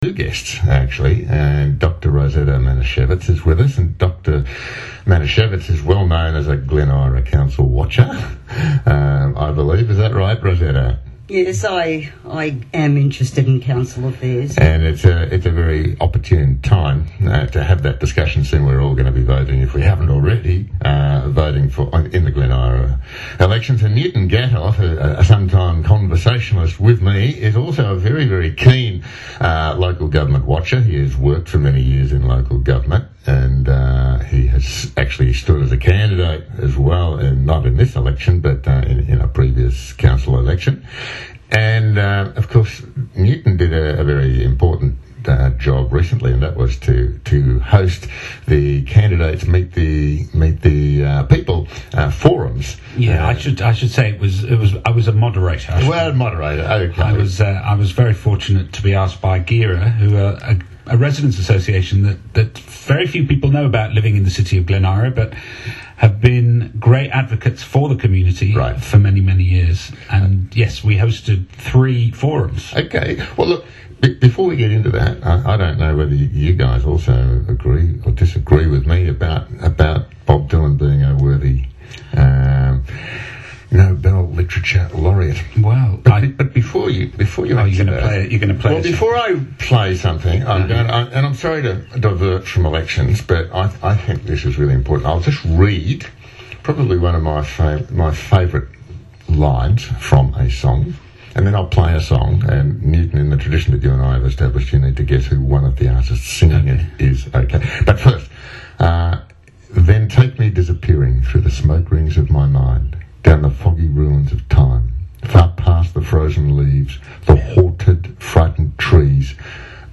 interview with 2 Glen Eira Residents on elections, and council performance